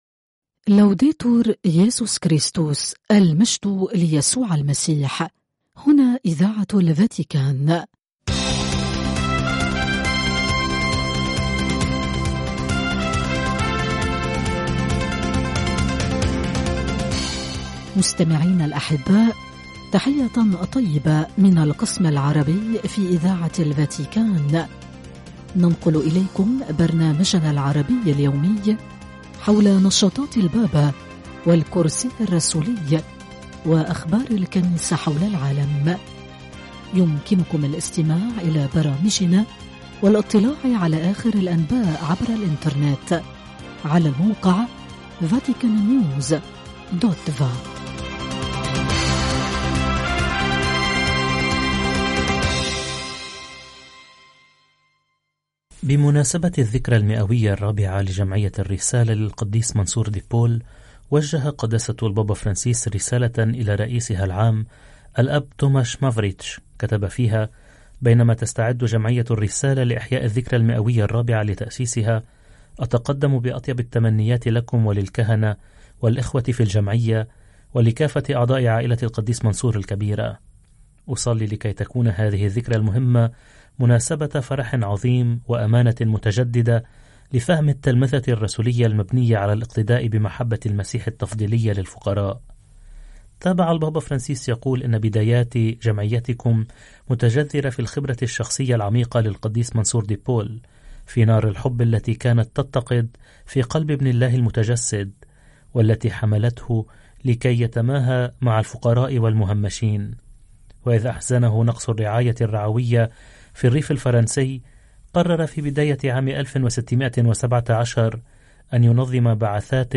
أخبار